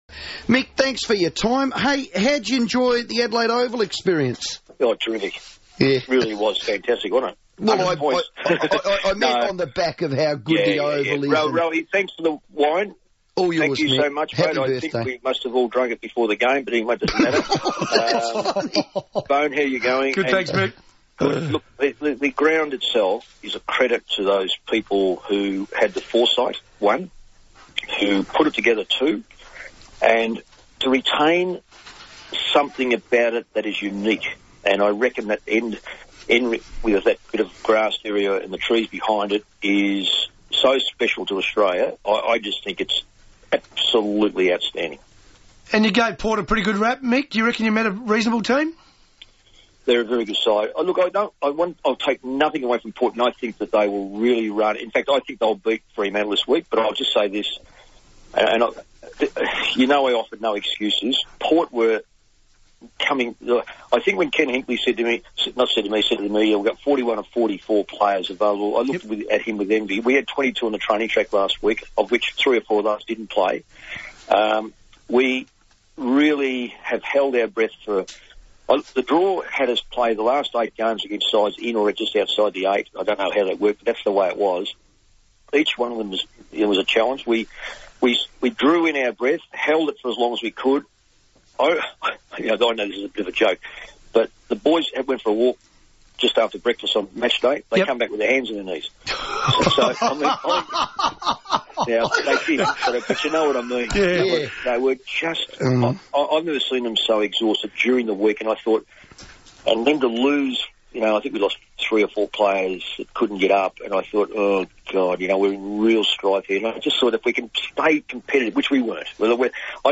Coach Mick Malthouse chatted to Adelaide's FIVEaa on Tuesday, August 26.